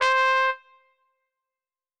Cow_Life_Sim_RPG/Sounds/SFX/Instruments/Trumpets/doot8.wav at main